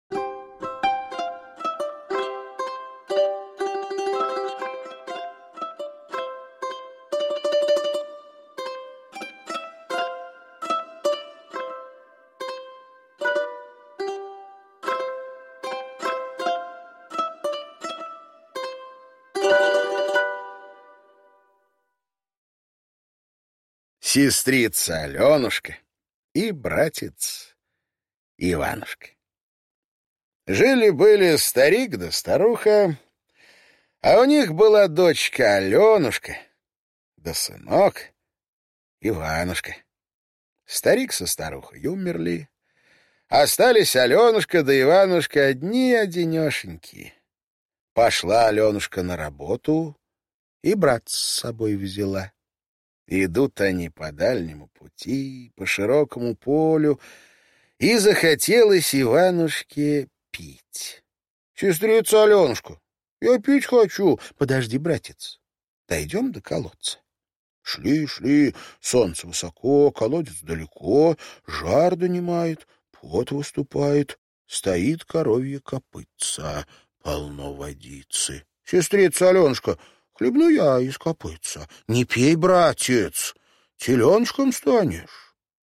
Аудиокнига Сестрица Аленушка и братец Иванушка | Библиотека аудиокниг